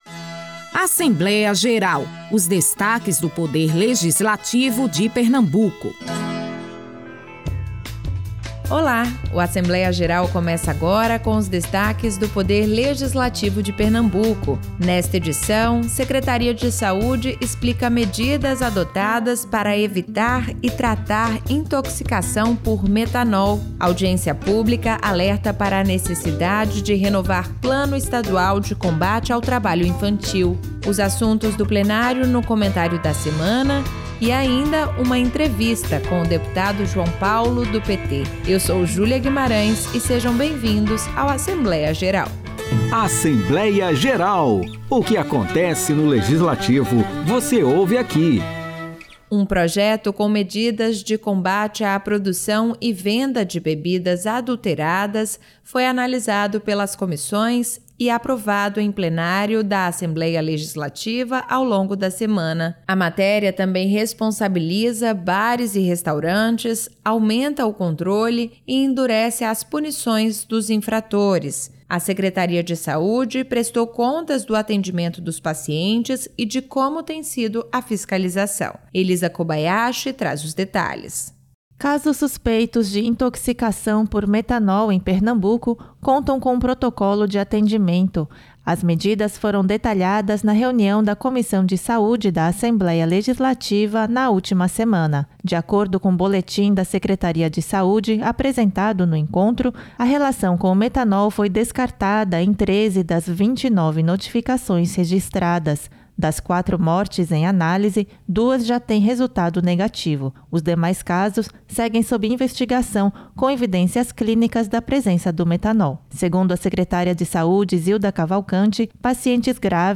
O programa também trouxe o Comentário da Semana, com os destaques do Plenário, e uma entrevista com o deputado João Paulo (PT), que defendeu a valorização dos direitos sociais. O programa Assembleia Geral é uma produção semanal da Rádio Alepe, com os destaques do Legislativo pernambucano.